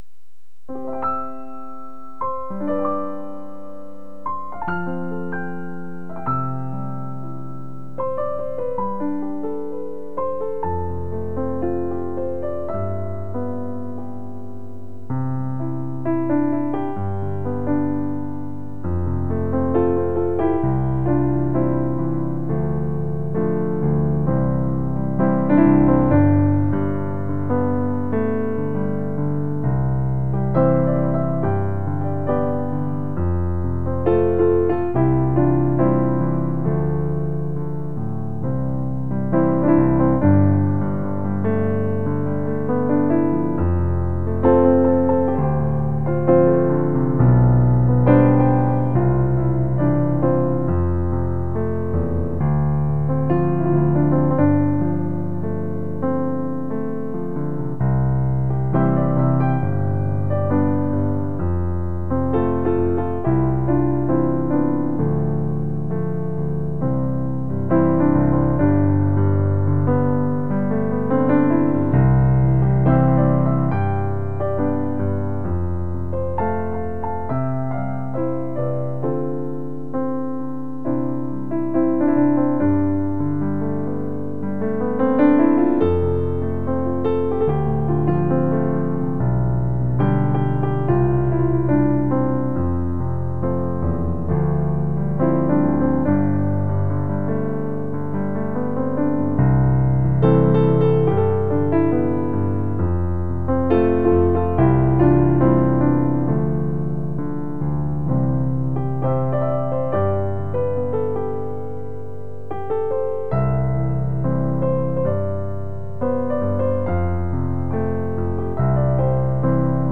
Acoustic Piano Cover